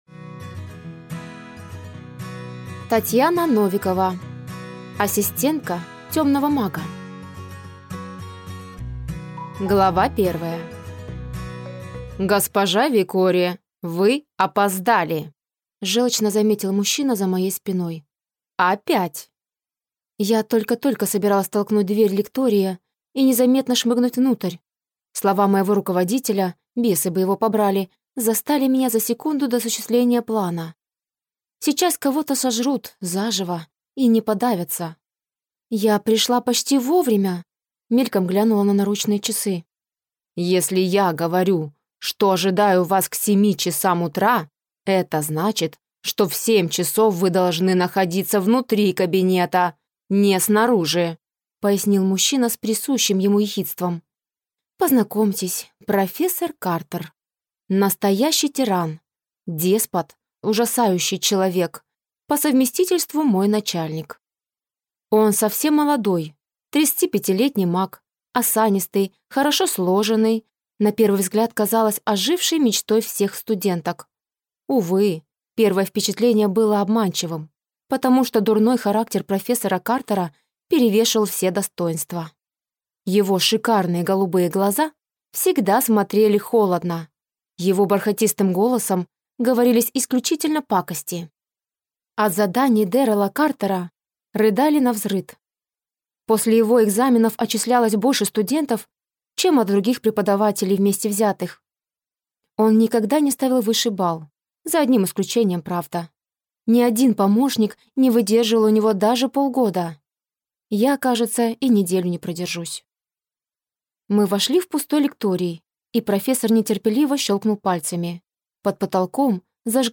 Аудиокнига Ассистентка темного мага | Библиотека аудиокниг